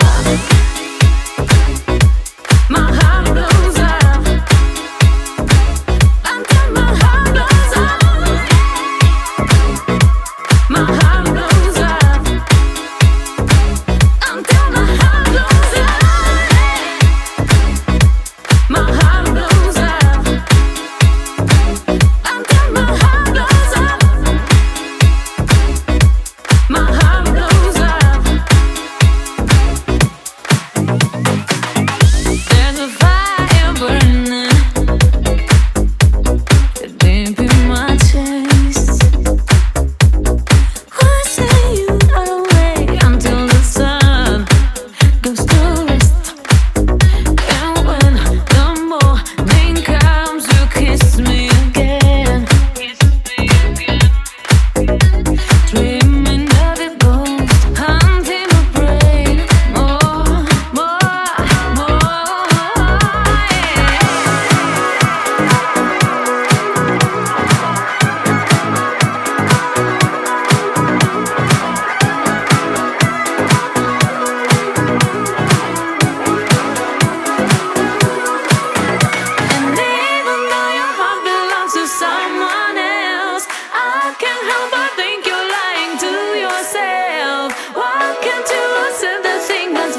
(B面)は各曲ともにソウルフル＆ディスコ〜ディープ・ハウスで捨曲一切無しです。
ジャンル(スタイル) DISCO / HOUSE